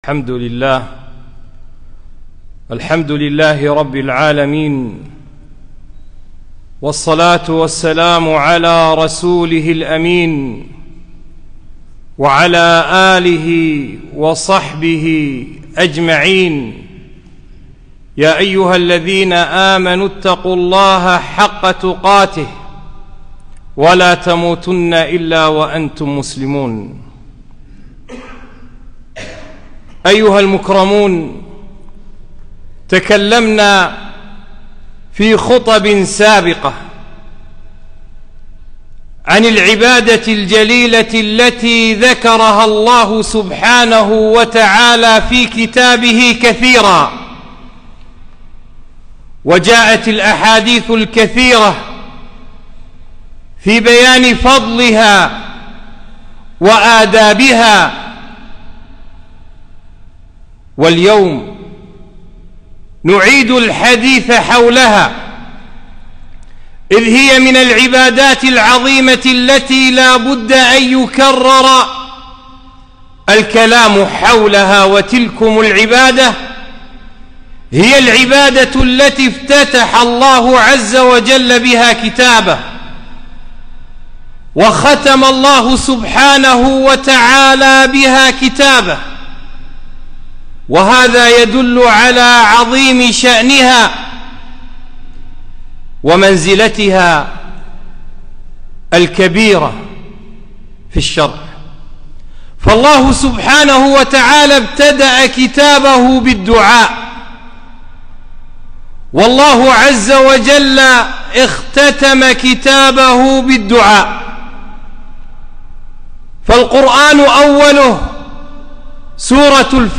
خطبة - فضل الدعاء